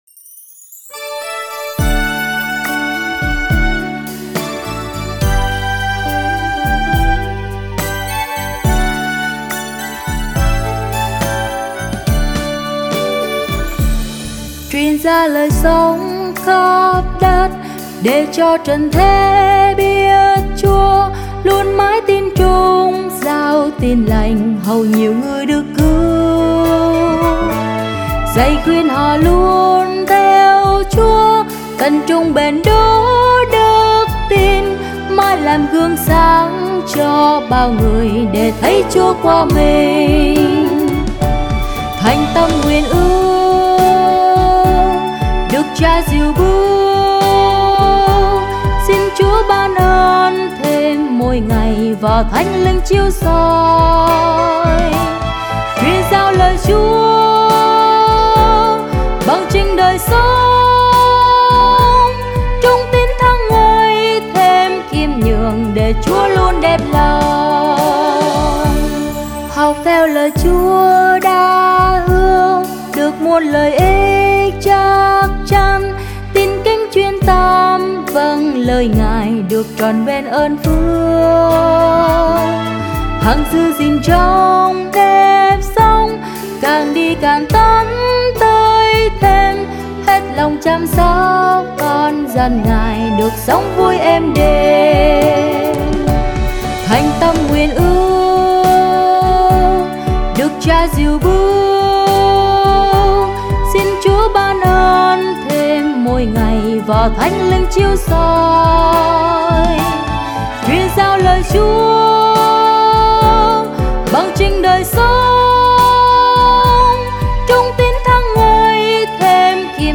Nhạc Thánh Sáng Tác Mới